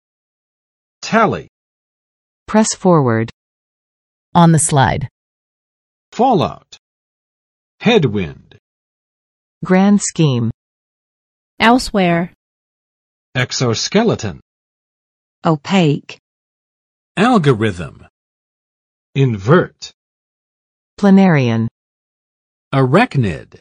[ˋtælɪ] n.（比赛中的）比分，得分